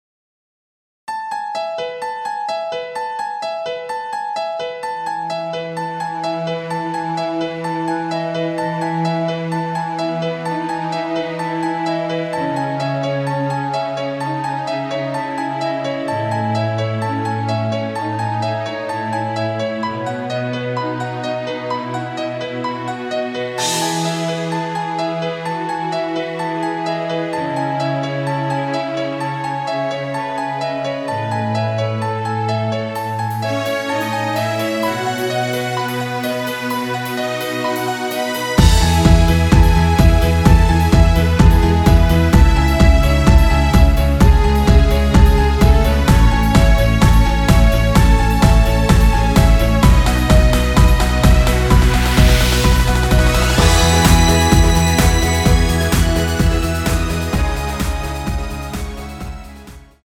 엔딩이 페이드 아웃이라 엔딩을 만들어 놓았습니다.(원키 멜로디 MR 미리듣기 확인)
원키에서(+3)올린 멜로디 포함된 MR입니다.
앞부분30초, 뒷부분30초씩 편집해서 올려 드리고 있습니다.